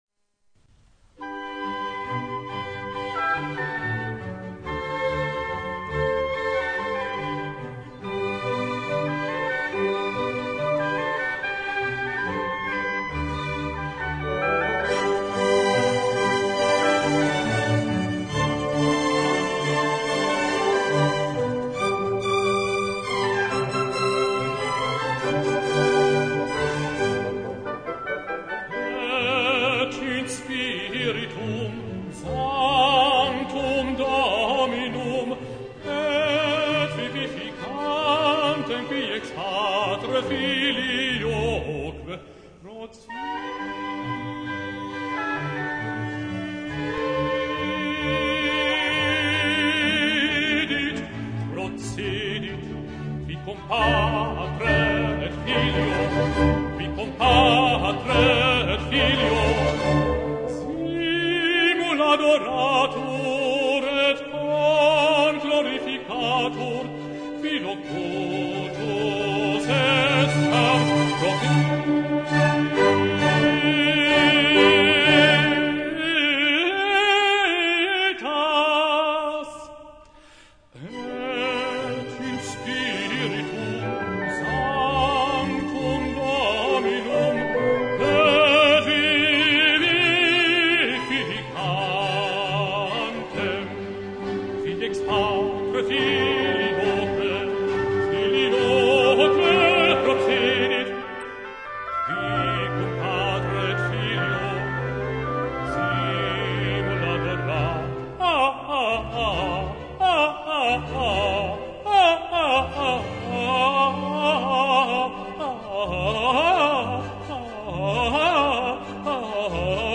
Arie Et in Spiritum Sanctum